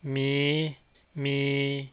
In the first picture "ມີຫມີ່" a high-rising tone is followed by a high-mid tone.